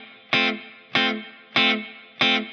DD_TeleChop_95-Fmaj.wav